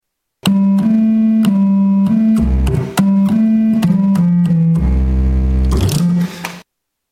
ELKA Panther 300 sound 2
Category: Sound FX   Right: Personal